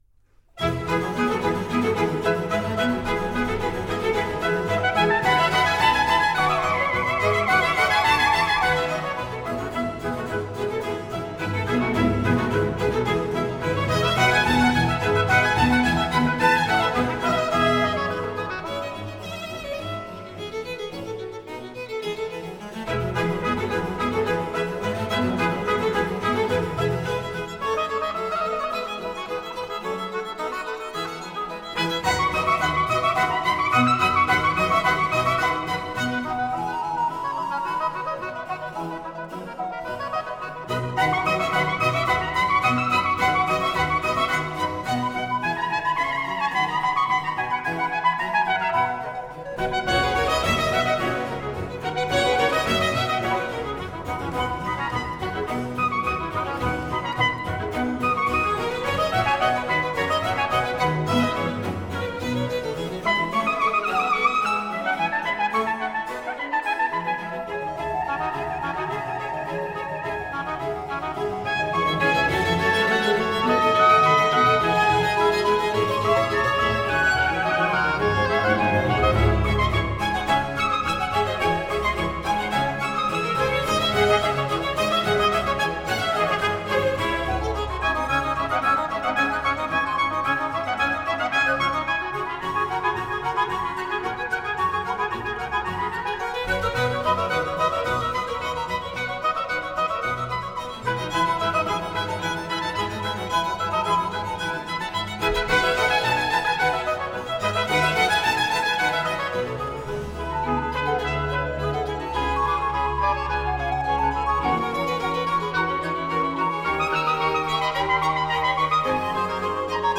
Johann Sebastian Bach: Brandenburg Concerto no. 2 in F major BWV 1047. I. Allegro.
I Barocchisti. Diego Fasolis, cond.